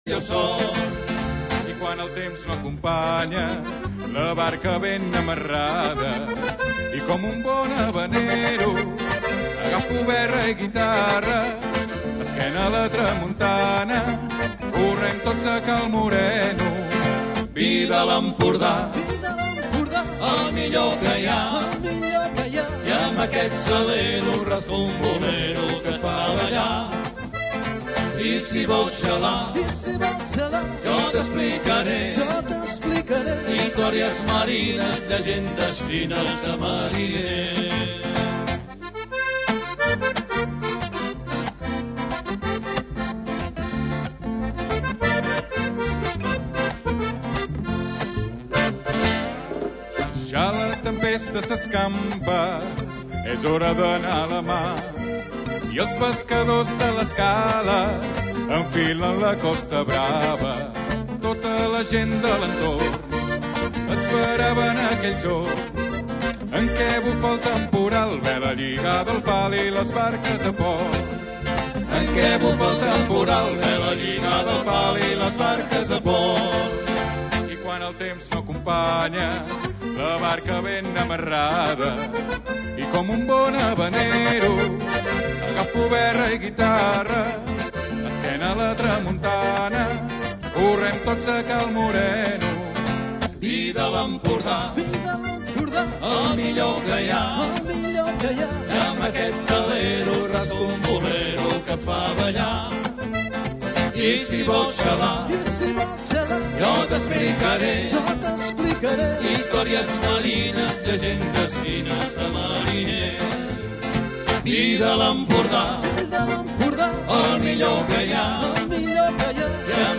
Transmissió Cantada Havaneres